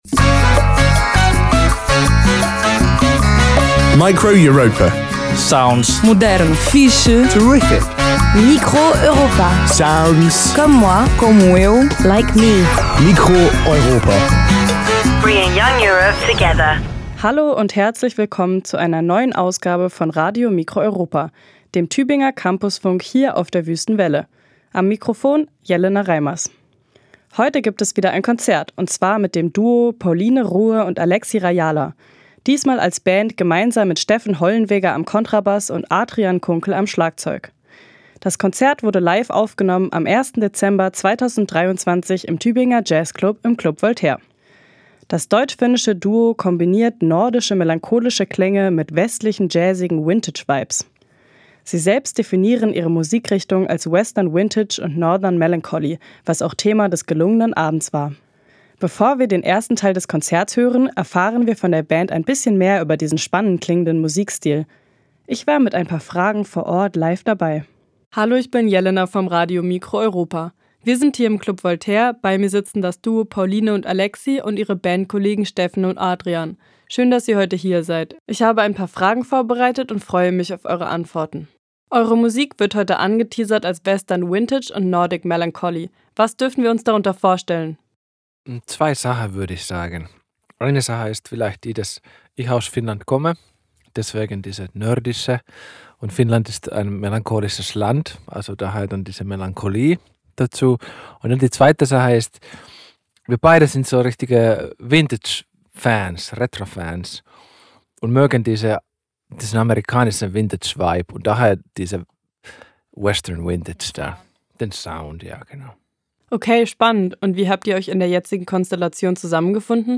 Gesang
Gitarre
Kontrabass
Schlagzeug
Live-Aufzeichnung, geschnitten